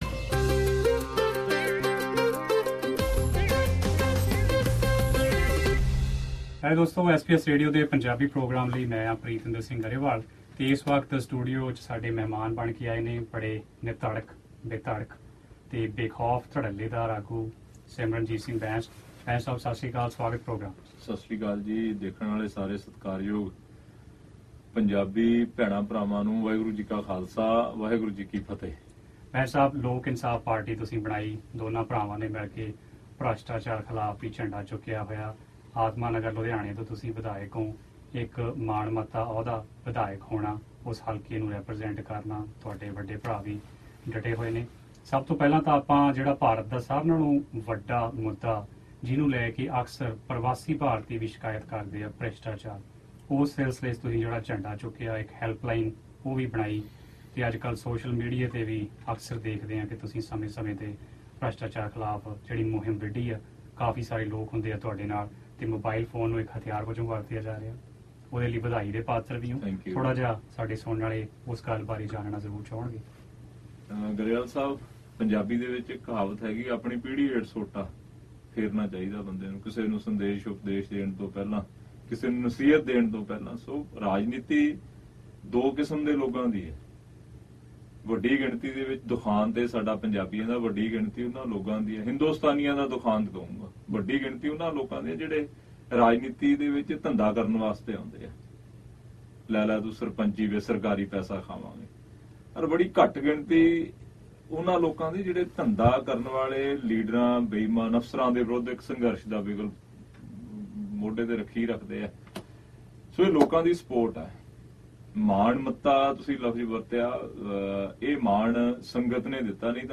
Interview with Lok Insaaf Party President Simarjit Singh Bains
Simarjit Singh Bains at SBS Studio, Melbourne, Australia Source: SBS